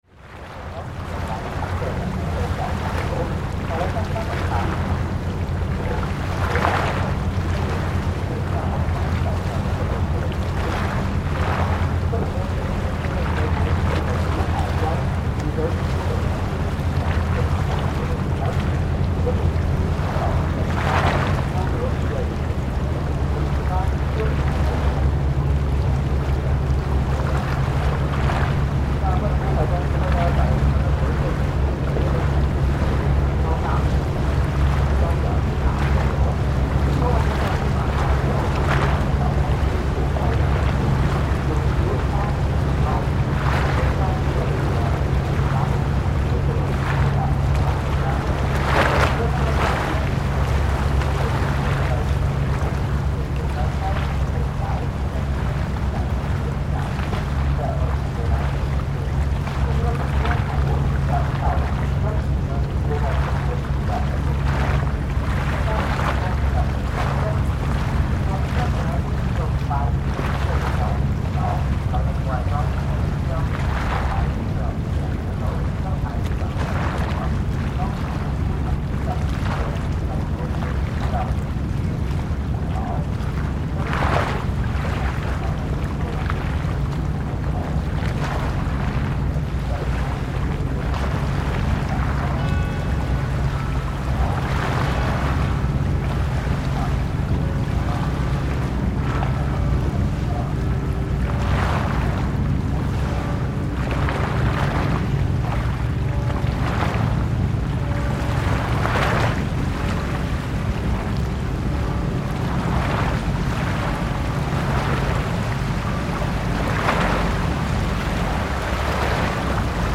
Outside the wonderful Casa Tre Oci gallery on Giudecca in Venice, we look across the lagoon to Piazza San Marco opposite.
The waves from passing boats slosh heavily against the concrete, notably louder (obviously) than the smaller canals of the main island. As well as passing boat traffic, we also hear the bells chime for 2pm from El Paron de la Casa, the campanile of San Marco across the lagoon.